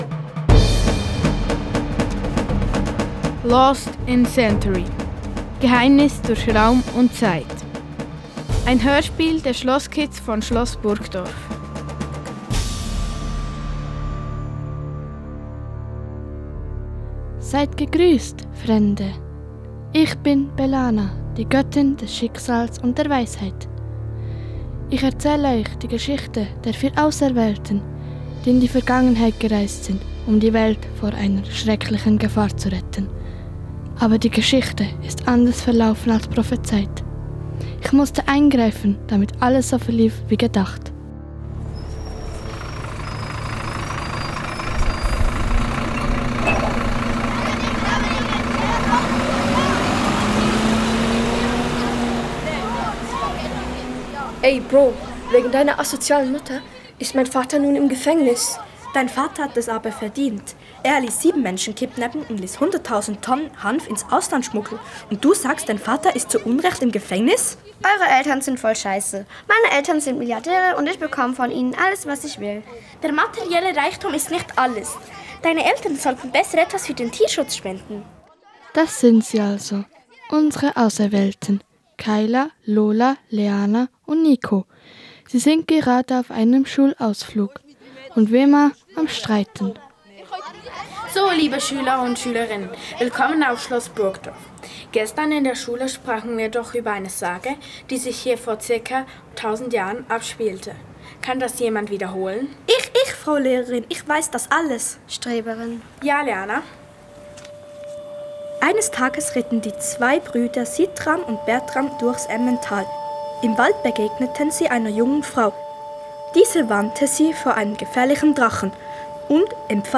In kleinen Gruppen haben sie Szenen geschrieben, Geräusche aufgenommen und Text eingesprochen. Unterstützt wurden sie dabei von einer professionellen Audioproduzentin und dem Museumsteam.